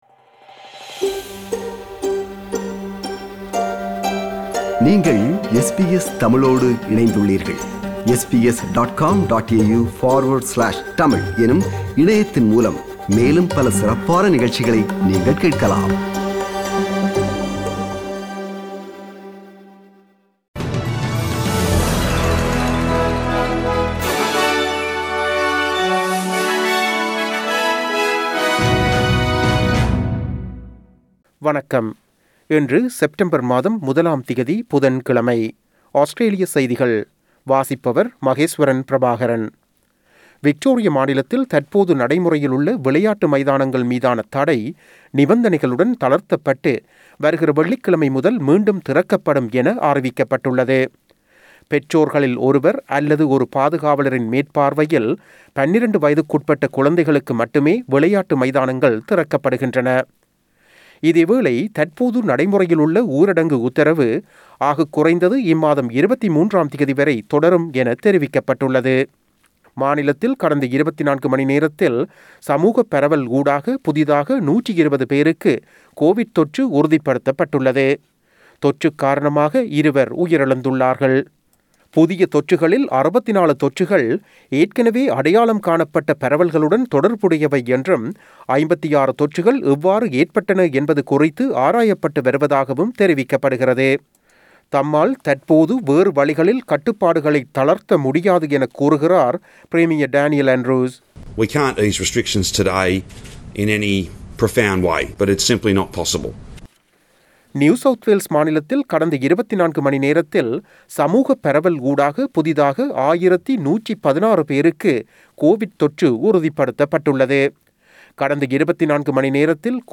Australian news bulletin for Wednesday 01 September 2021.